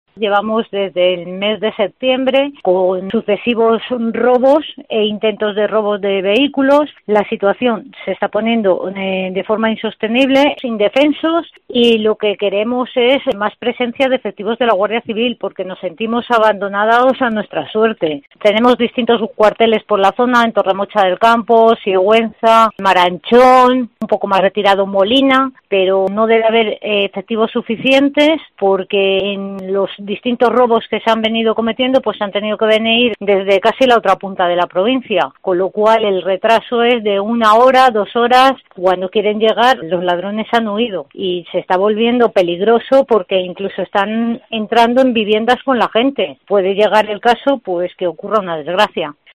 Así se expresaba Carmen Rojo, Concejal de Alcolea del Pinar